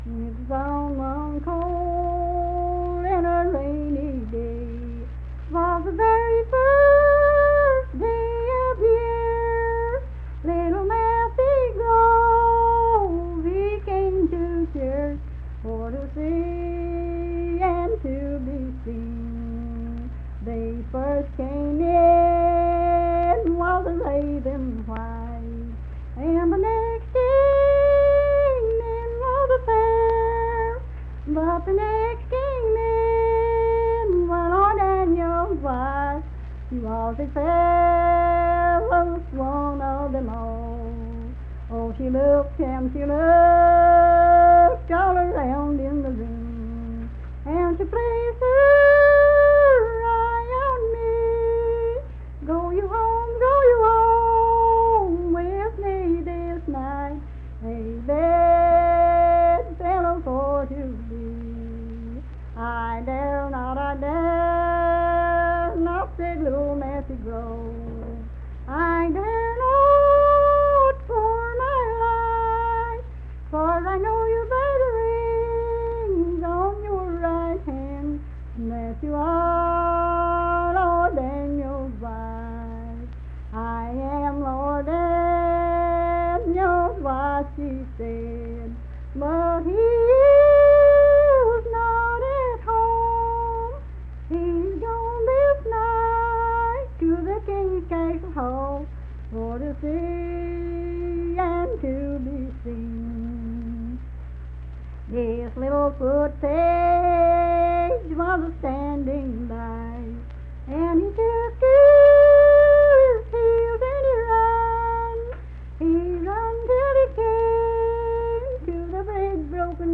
Unaccompanied vocal music
Verse-refrain, 23(4).
Voice (sung)
Mingo County (W. Va.), Kirk (W. Va.)